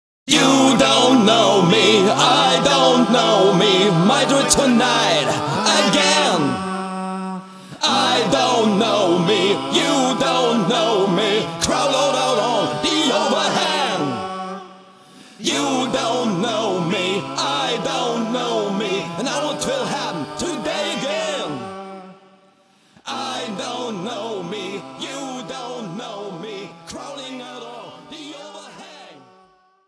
mehrere home recording-Sessions im Proberaum
Gitarre, Backing-Vocals
Bass, Backing-Vocals
Schlagzeug
Lead-Gesang